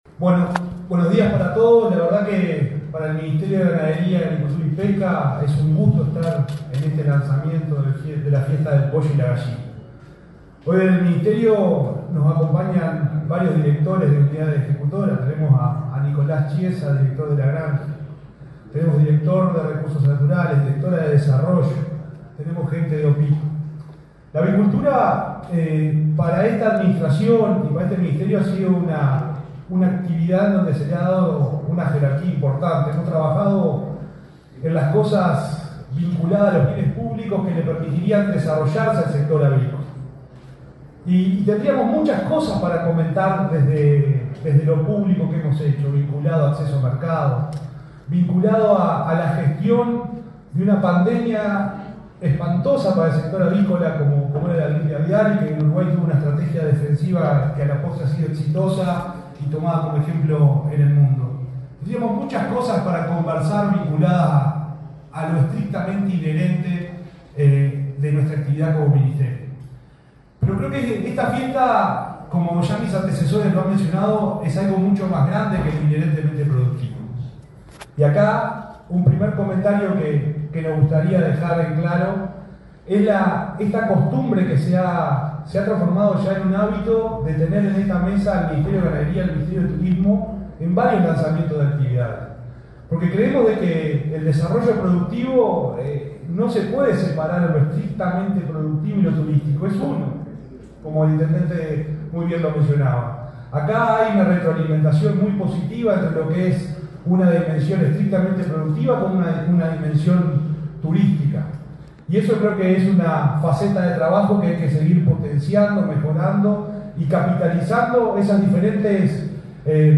Palabra de autoridades en el Ministerio de Turismo
El subsecretario de Ganadería, Ignacio Buffa, y el ministro de Turismo, Eduardo Sanguinetti, participaron del lanzamiento del festival del Pollo y la